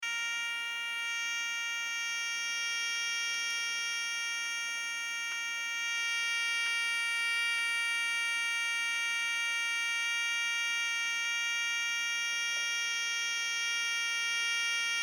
Störton: Auf der Audioleitung der Türsprechanlage mit Netzgerät NH 205 TVM liegt ein Störton (siehe Anhang). Dieser Ton ist auf allen 60 Haustelefonen HT2003 GVS nach dem Anklingeln und dauerhaft auf dem Lautsprecher der Türstation zu hören. 2.
STR_Stoerton.mp3